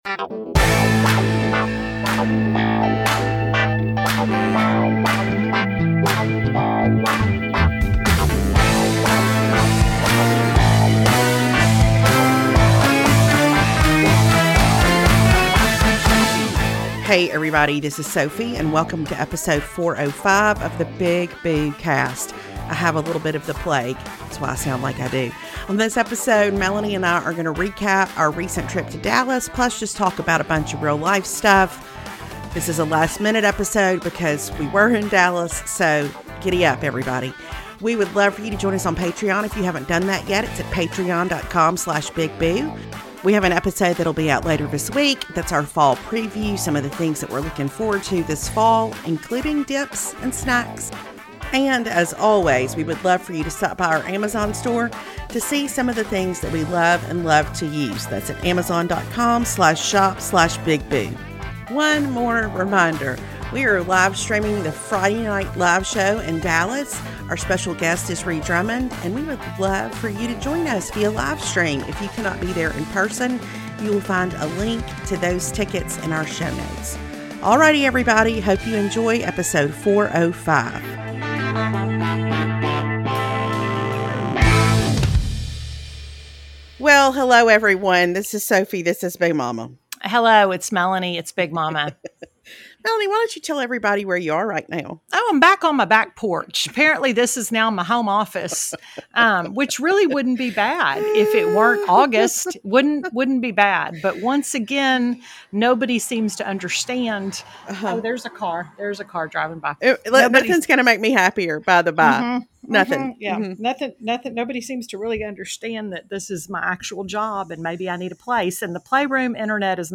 while window cleaners were working at our house